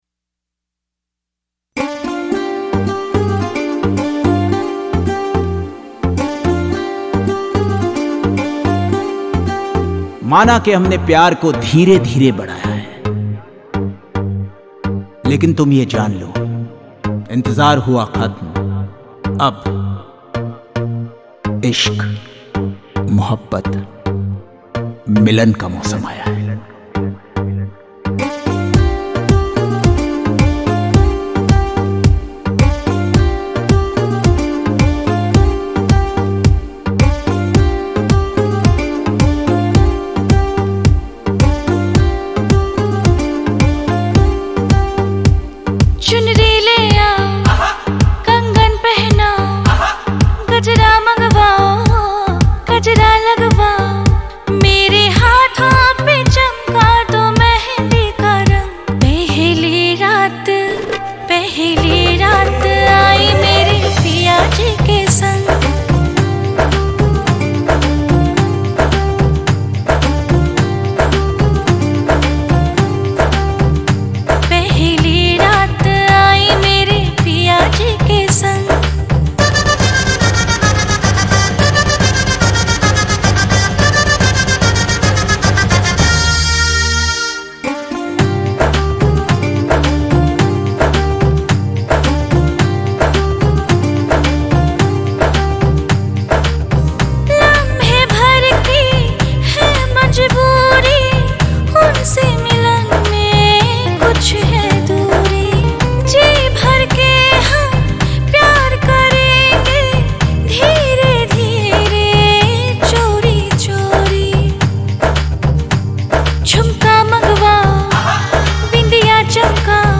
2. INDIPOP MP3 Songs